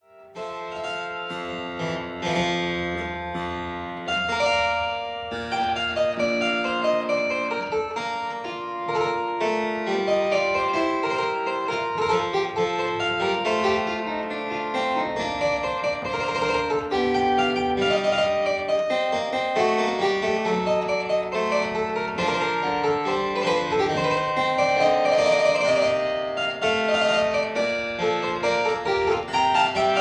two manual harpsichord